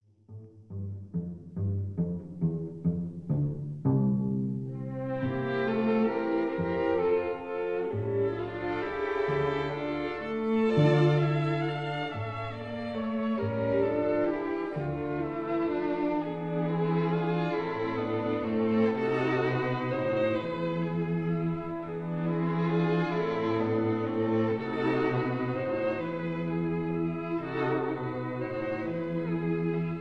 in C major
violins
viola
cello